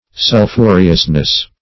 -- Sul*phu"re*ous*ness , n. [1913 Webster]
sulphureousness.mp3